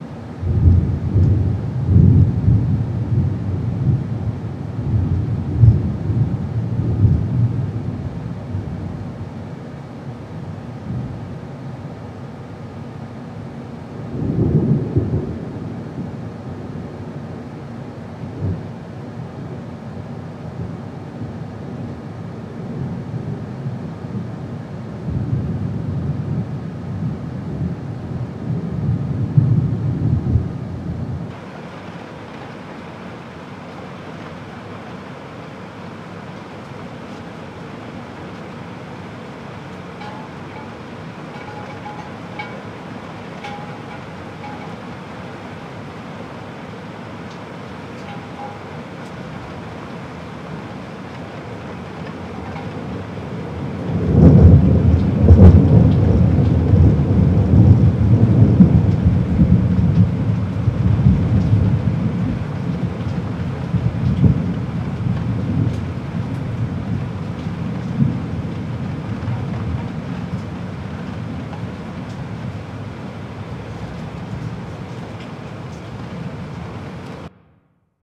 環境音 雷 雨 / 雷 thunder